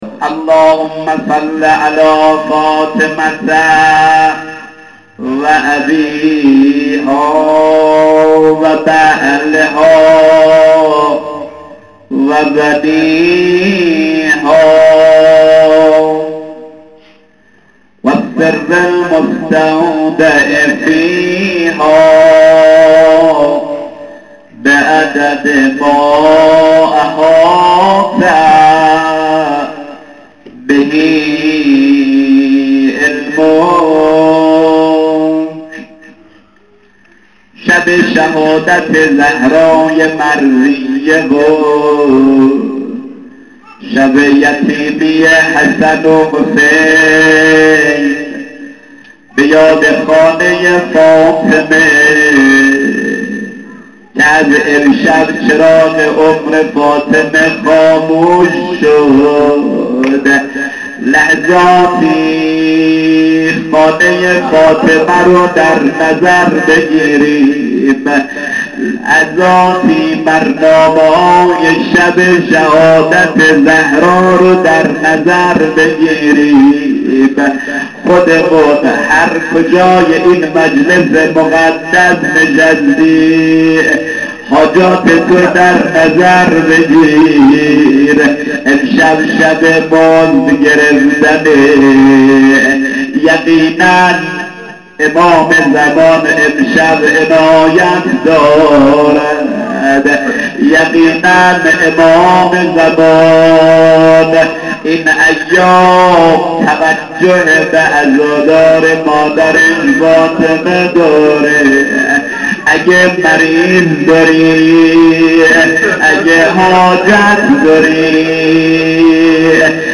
• شب شهادت حضرت فاطمه س (اسفند 92)
روضه حضرت زهرا س